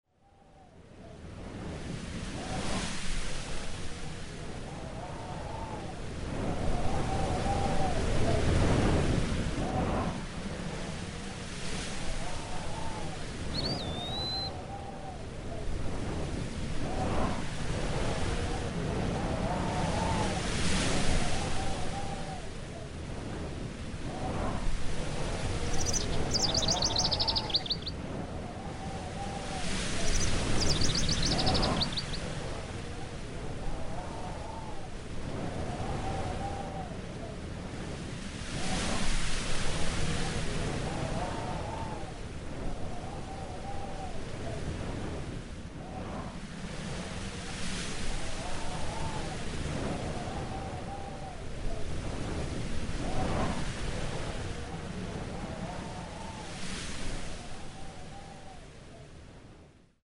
Category: Animals/Nature   Right: Personal